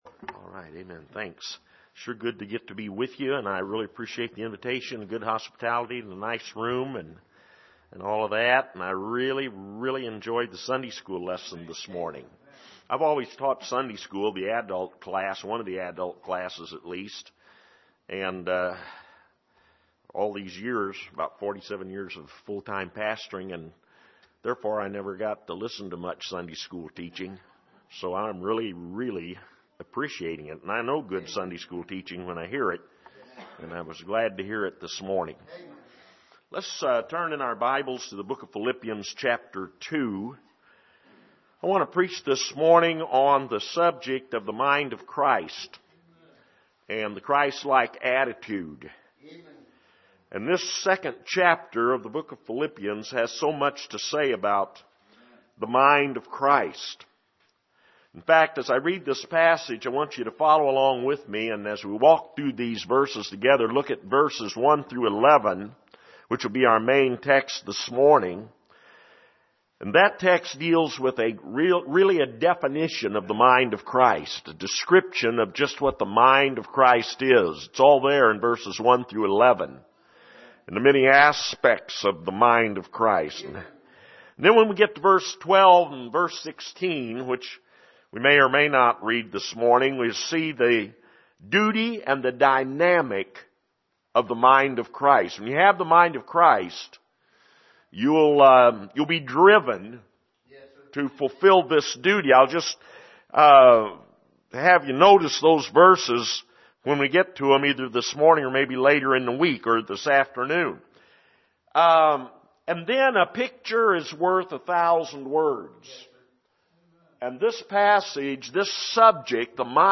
2018 Bible Conference Passage: Philippians 2:1-30 Service: Sunday Morning